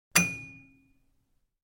Звуки духовки
Звук противня в духовке